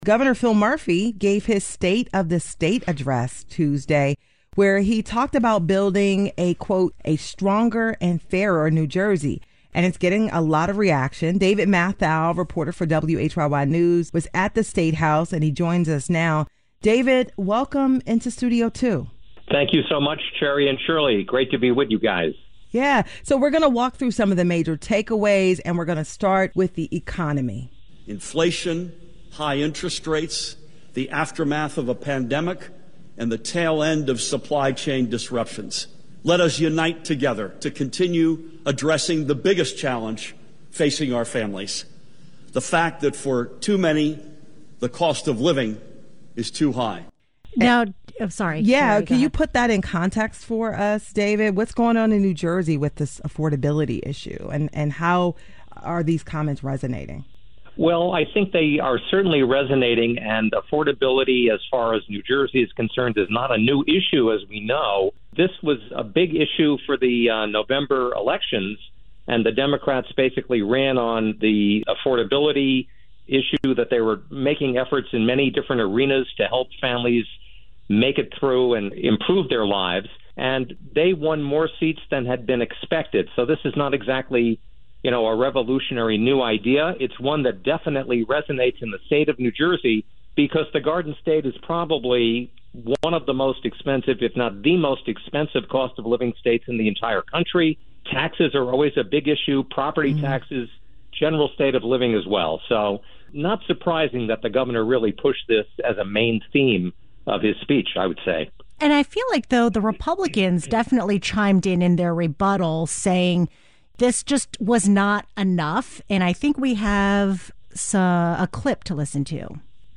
New Jersey Gov. Phil Murphy delivers his State of the State address to a joint session of the Legislature at the statehouse, in Trenton, N.J., Tuesday, Jan. 9, 2024.